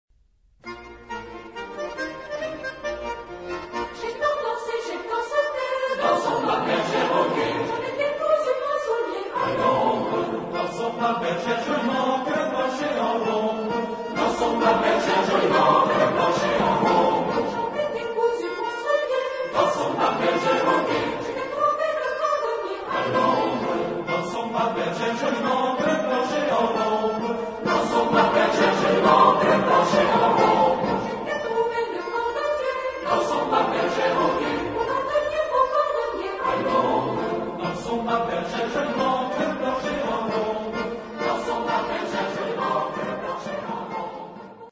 Genre-Style-Forme : Profane ; Populaire ; Chanson à répondre ; ballet
Type de choeur : SATB  (4 voix mixtes )
Solistes : 1 au choix  (1 soliste(s))
Tonalité : si bémol majeur